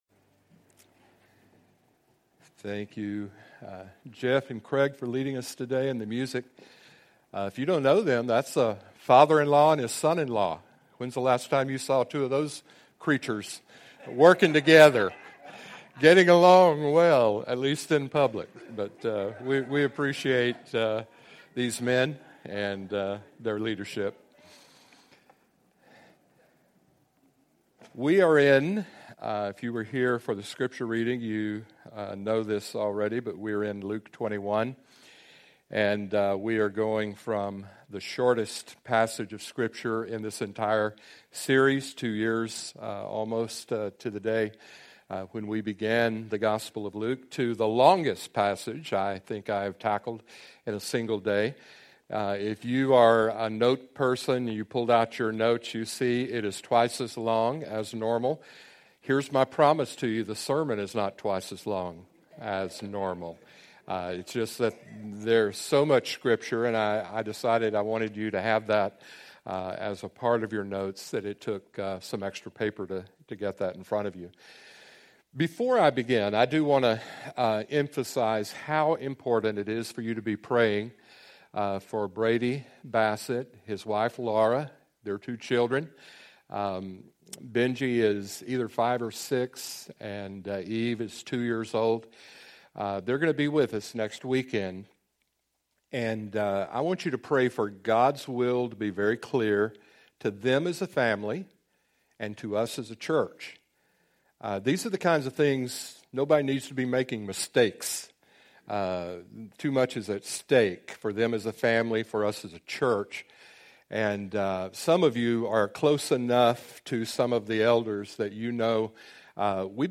Sermons | Crossroads Church